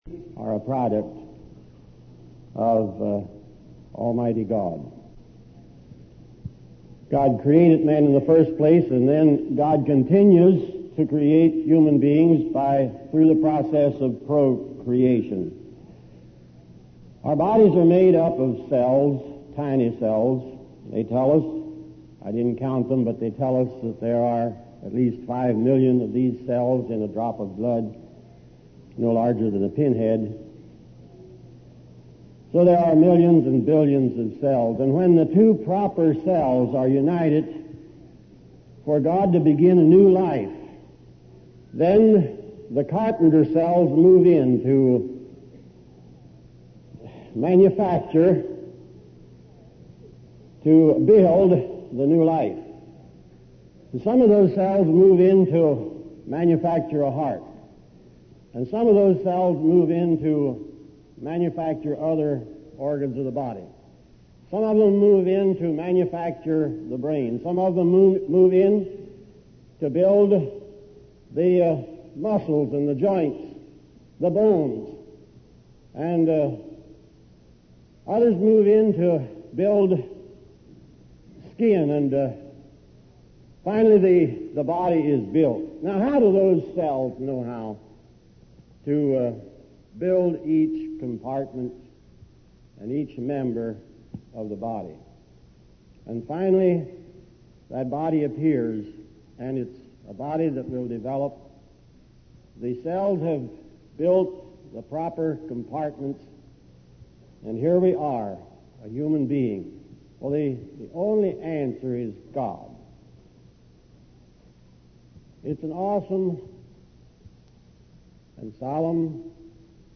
Sermon
This is a typical all day meeting in one of the churches.